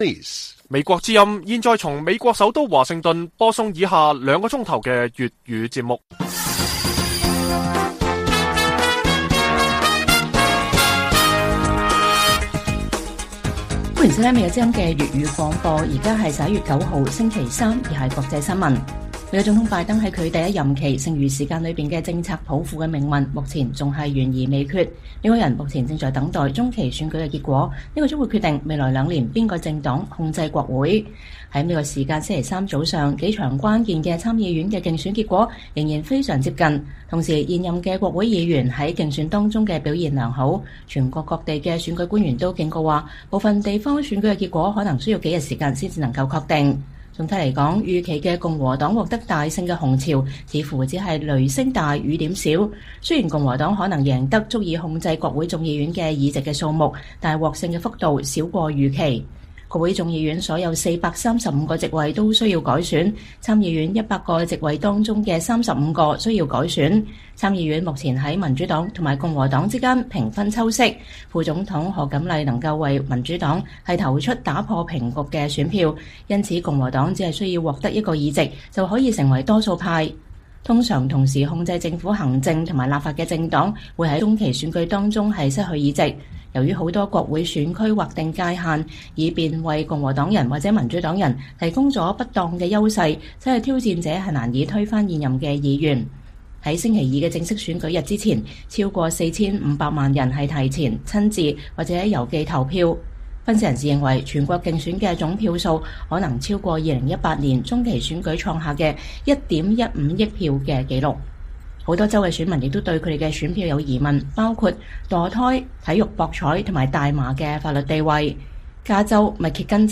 粵語新聞 晚上9-10點: 美國國會中期選舉結果目前還是旗鼓相當